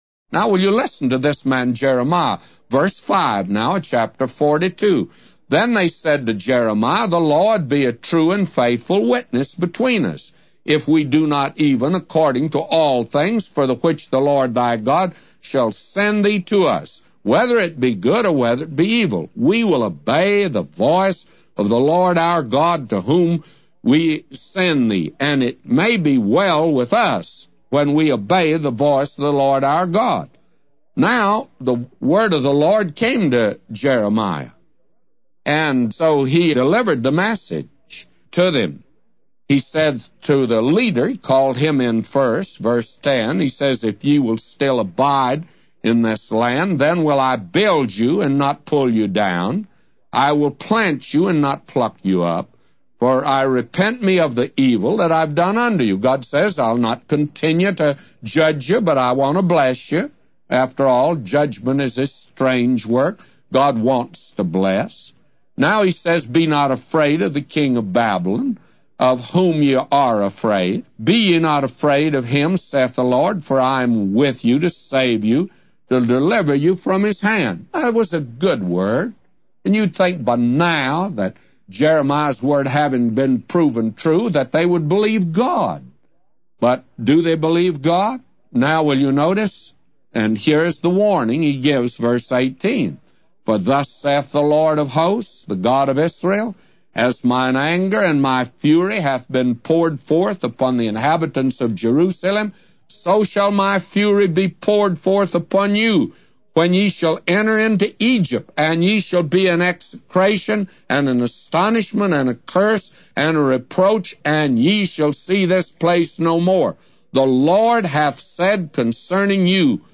A Commentary By J Vernon MCgee For Jeremiah 42:5-999